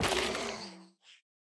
Minecraft Version Minecraft Version snapshot Latest Release | Latest Snapshot snapshot / assets / minecraft / sounds / block / sculk_sensor / break2.ogg Compare With Compare With Latest Release | Latest Snapshot
break2.ogg